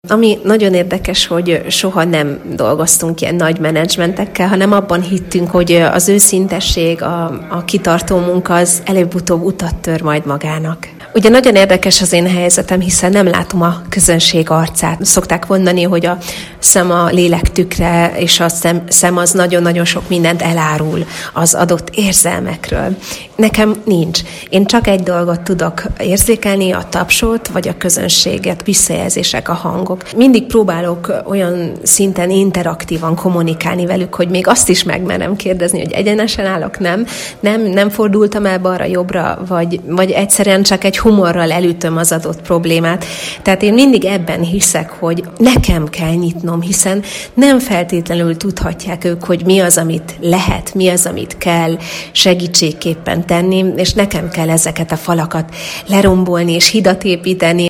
Agárdi Szilvia Dunaföldváron lépett fel és adott nagysikerű koncertet a Máltai Szeretet Szolgálat helyi szervezetének ünnepségén.